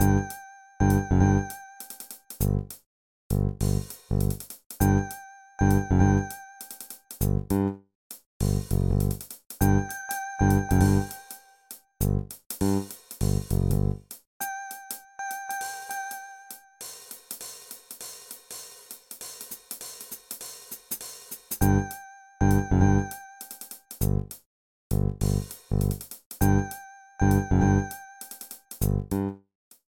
Level preview music